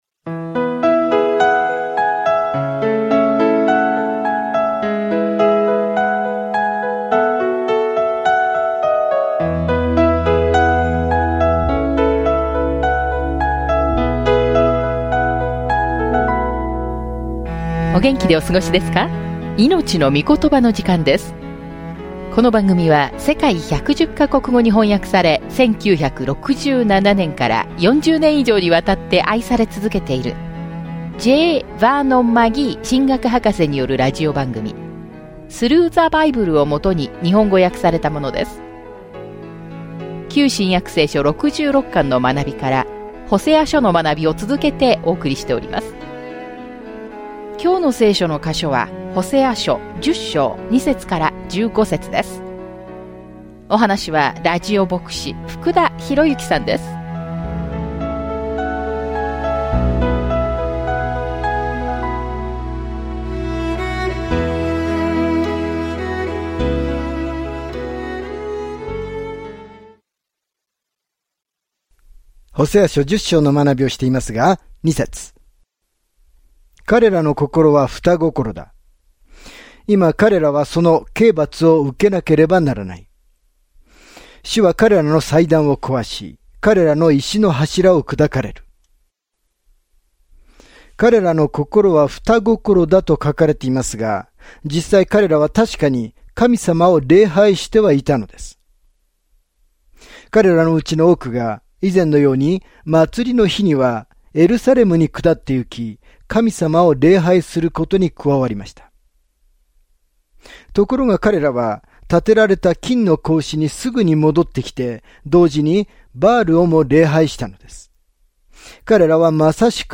聖書 ホセア書 10:2-15 日 12 この読書プランを開始する 日 14 この読書プランについて 神は、神の民が神に対して不忠実であるにもかかわらず、それでも彼らを愛すると約束したときに神がどのように感じられるかを示す例として、ホセアの痛ましい結婚生活を例に挙げられました。音声学習を聞きながら、神の言葉から選ばれた聖句を読みながら、ホセア書を毎日旅しましょう。